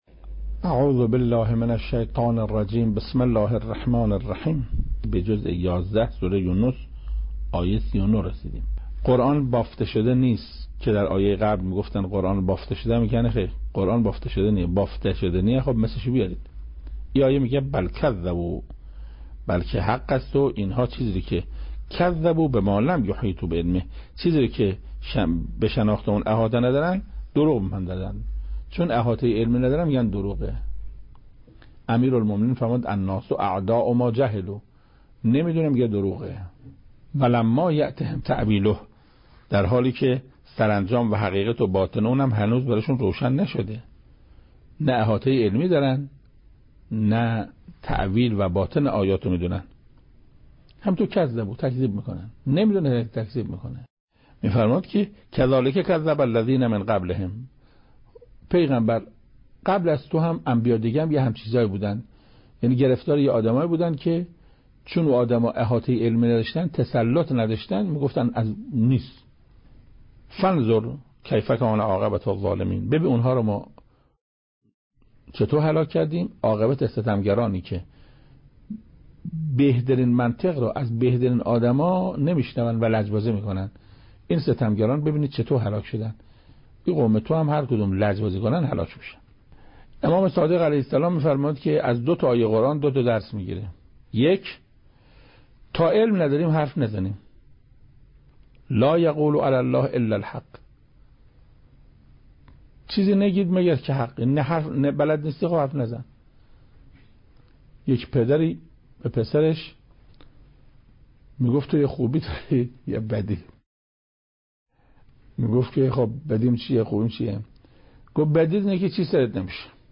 تفسیر سوره(استاد قرائتی) بخش اول